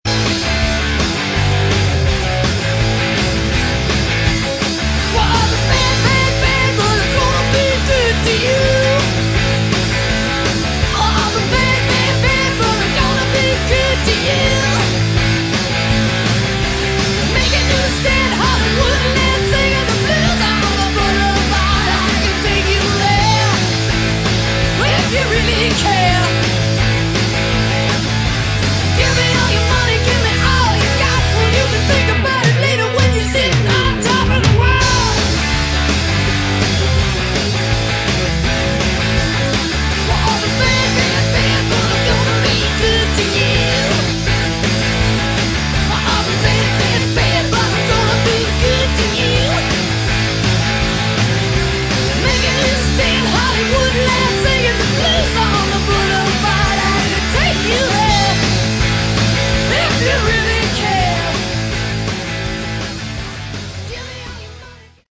Category: Hard Rock
vocals
guitar
drums
keyboards, bass